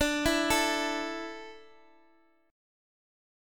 A5/D chord
A-5th-D-x,x,x,7,5,5-8.m4a